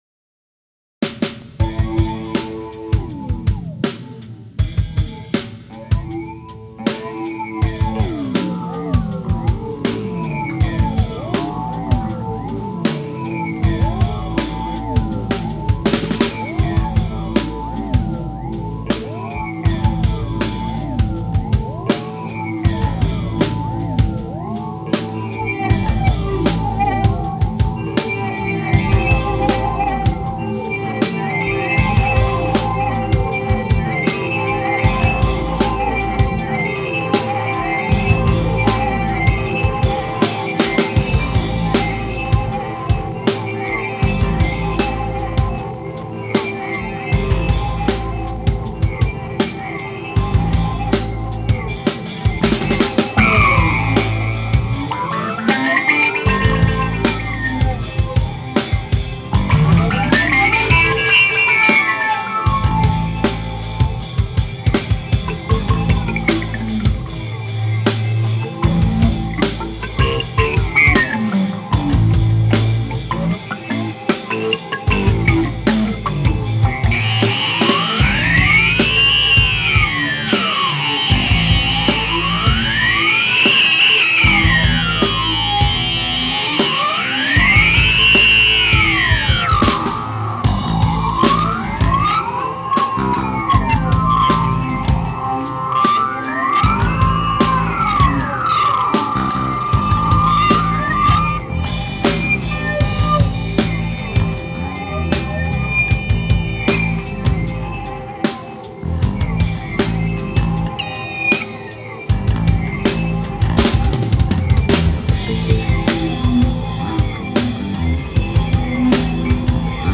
bass
drums
guitar
Literally, in 3 or 4 hours we perform and record all of our songs and then patiently wait until the next year (sometimes two) to do it again.